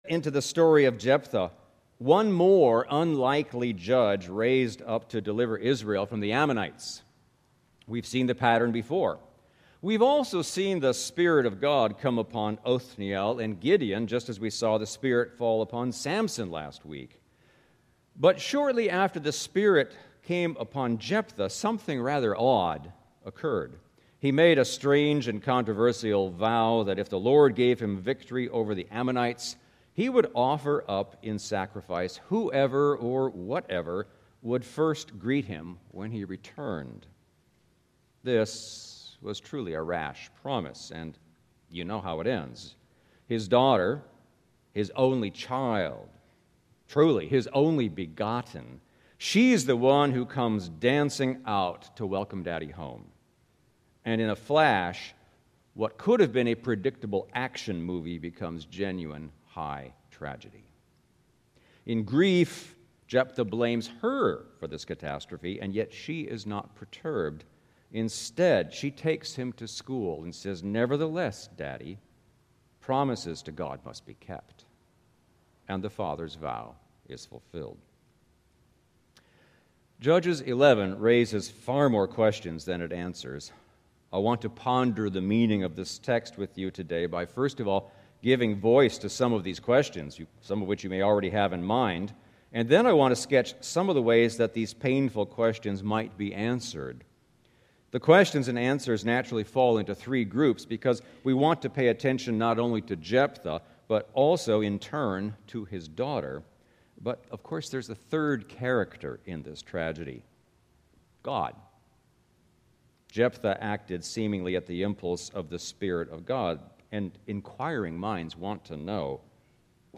Knox Pasadena Sermons